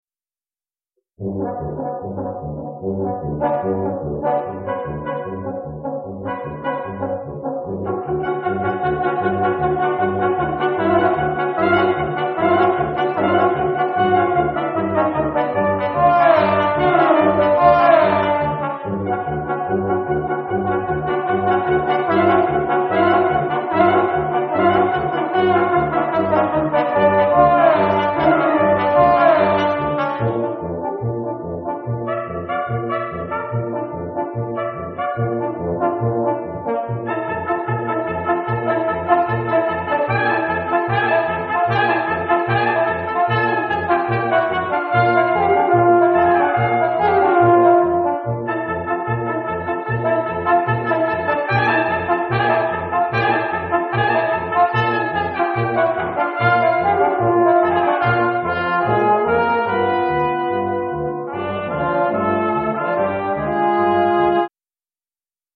Polished Brass
Westwood Presbyterian Church